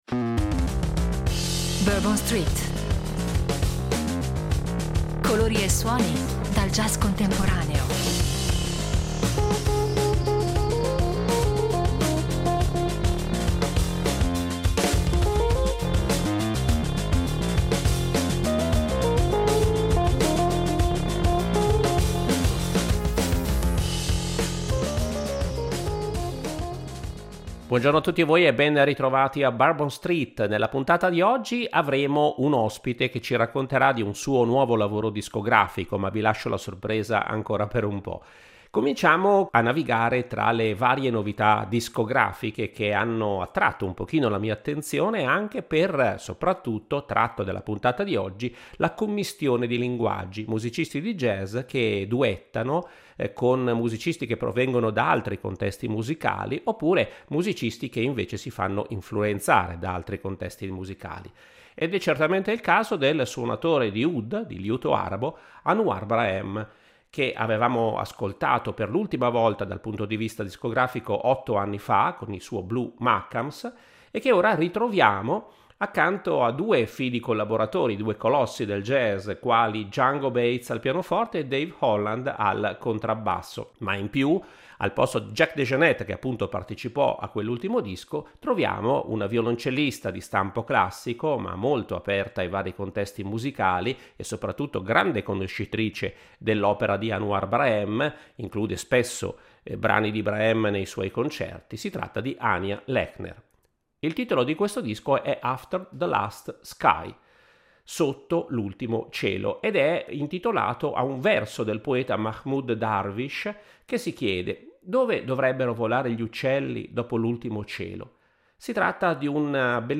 Ai confini tra jazz e altre esperienze musicali